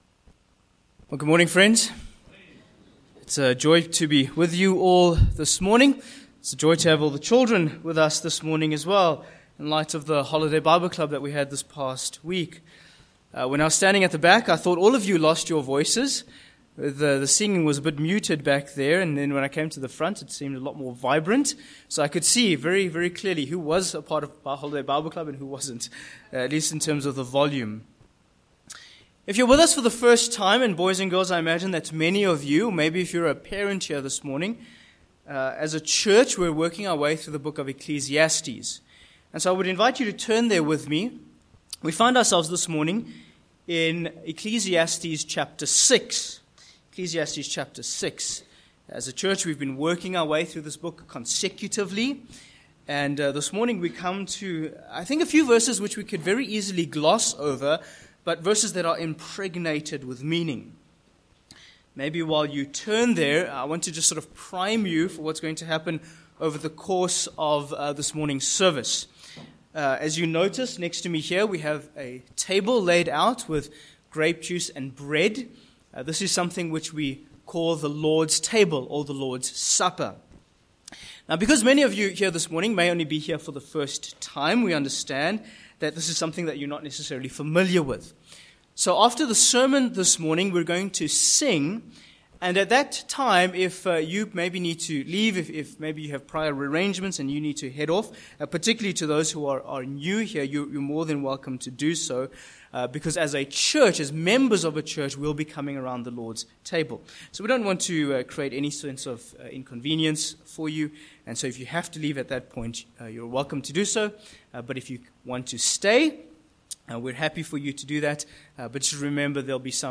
Sermon Points 1. Paradise Lost v10